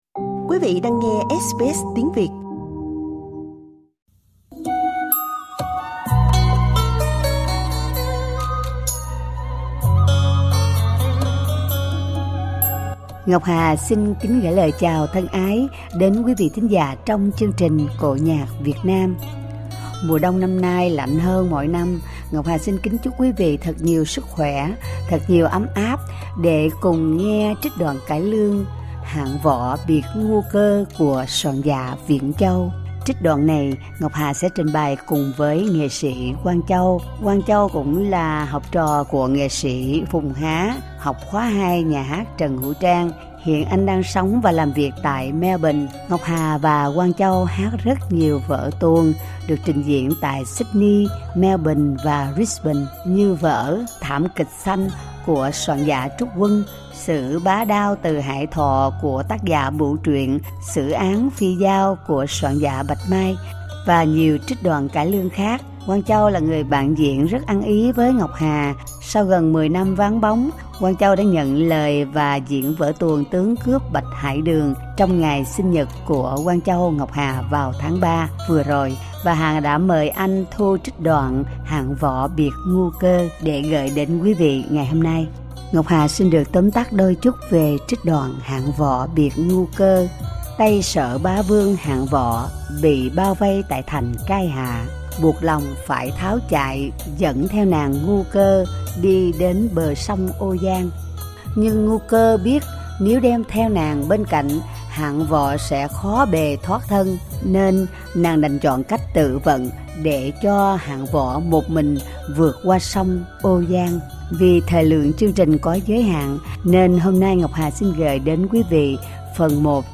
Cải Lương